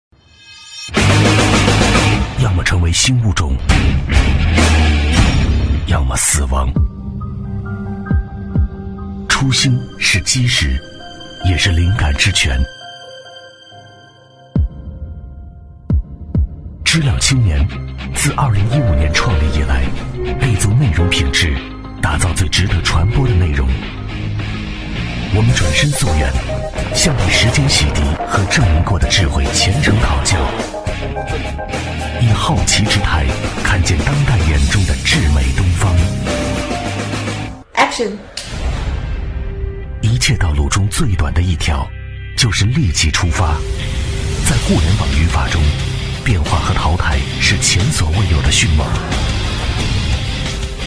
男42-片头台宣【知了青年（个性）】
男42-磁性质感 大气浑厚
男42-片头台宣【知了青年（个性）】.mp3